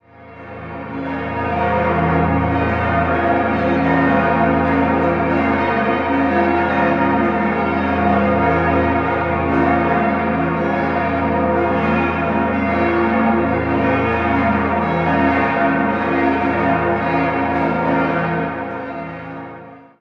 8-stimmiges Geläute: f°-b°-des'-f'-as'-b'-c''-d'' Die beiden kleinen Glocken wurden 1958 von Petit&Edelbrock in Gescher gegossen, die Glocken 3 und 4 von Martin Legros im Jahr 1773.
Im Dachreiter sind noch zwei kleine, gotische Glocken untergebracht.